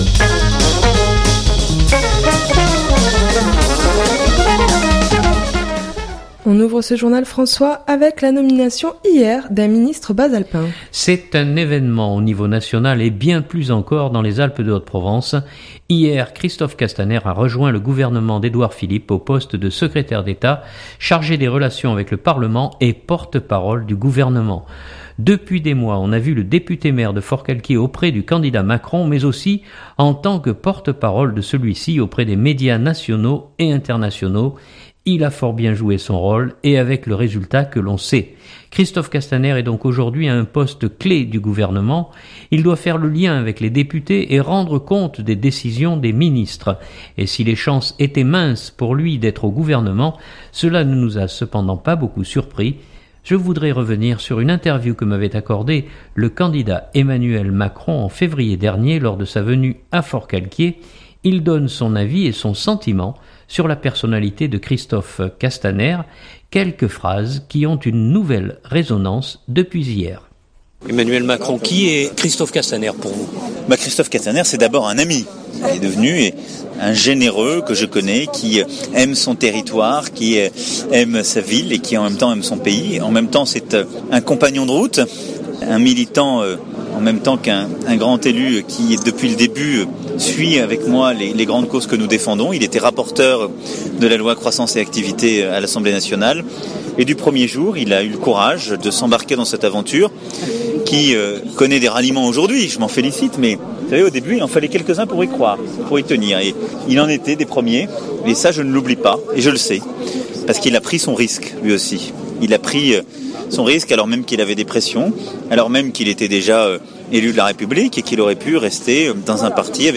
Je voudrais revenir sur une interview que m’avait accordée le candidat Emmanuel Macron en février dernier lors de sa venue à Forcalquier. Il donne son avis et son sentiment sur la personnalité de Christophe Castaner.